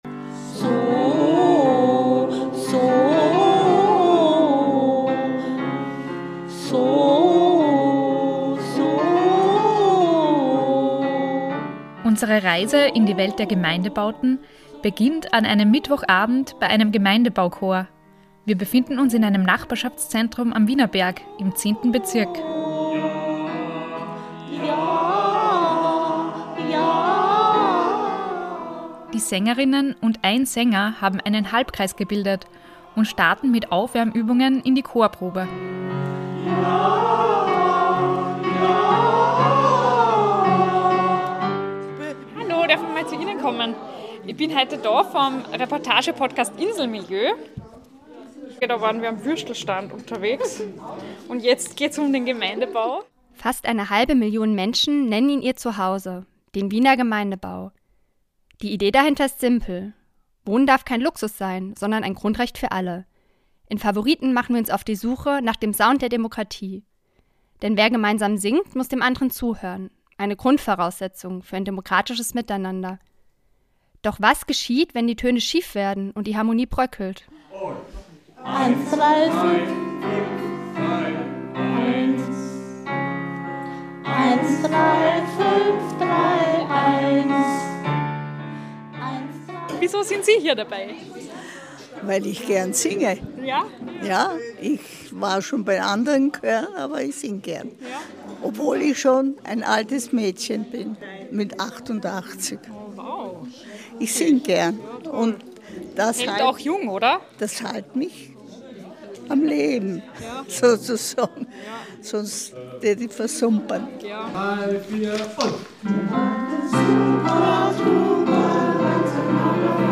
In der dritten Reportage unseres Demokratie-Schwerpunkts tauchen wir tief ein in die Wiener Hinterhöfe, Waschsalons und Hobbykeller – einen Lebensraum, in dem Sprachen, Kulturen und Lebensentwürfe auf engstem Raum aufeinandertreffen.